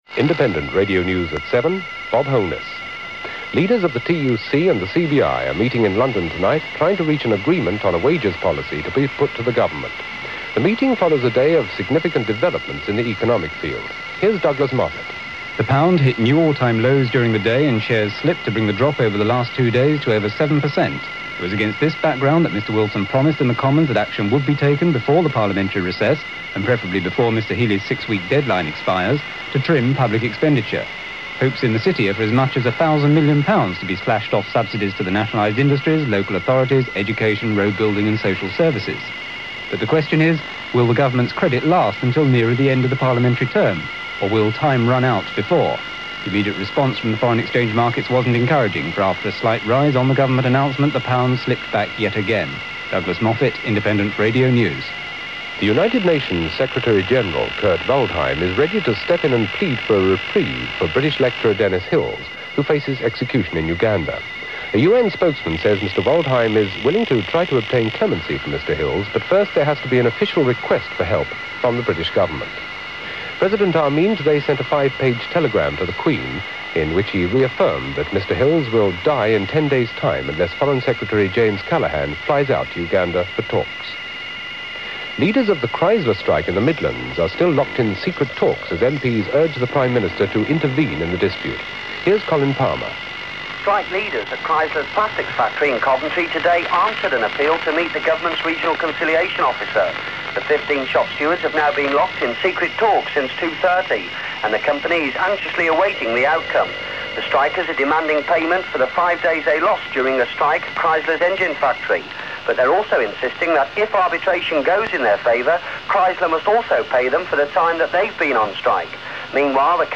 Listen here to Bob reading the IRN news, which he did as part of the LBC programme. Back then, some stations around the UK added their local news to the IRN bulletin, rather than mix it up, hence this peak-rime IRN bulletin would have been heard on quite a few stations. This audio is culled from a medium wave recording of the Radio Tees first day on air.